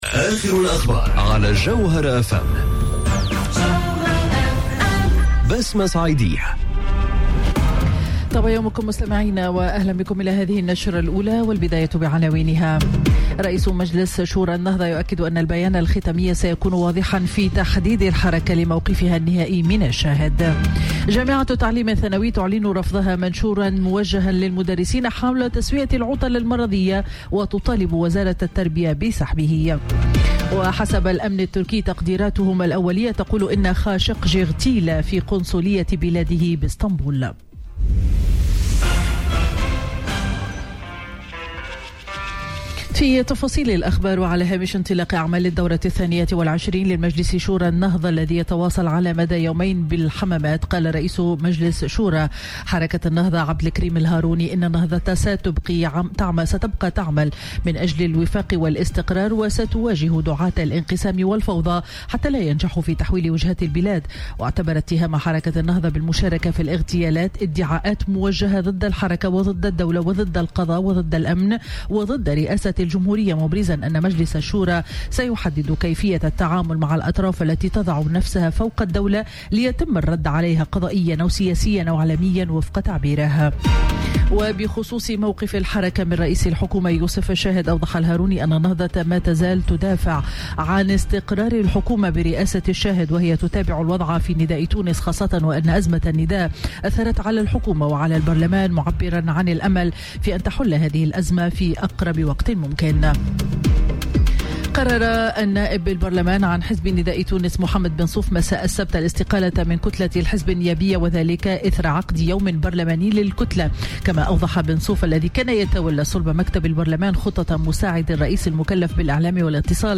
نشرة أخبار السابعة صباحا ليوم الأحد 07 أكتوبر 2018